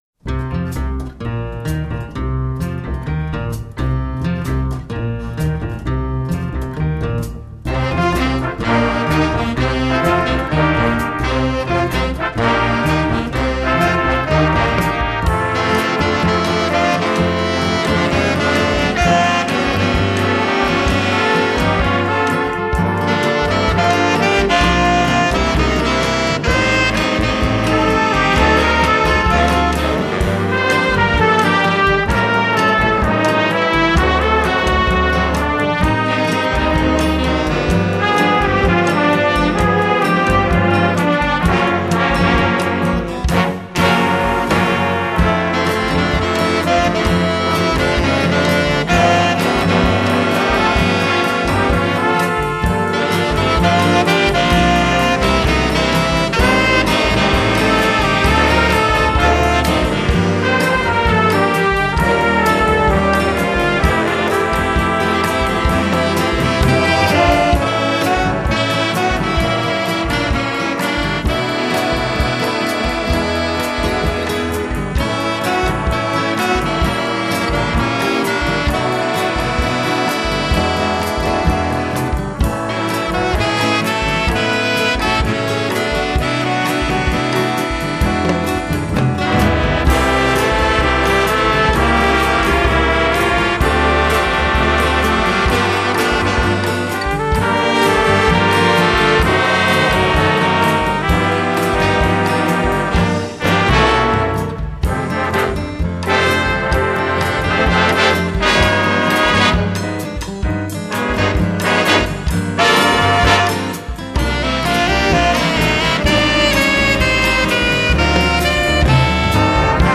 Big Band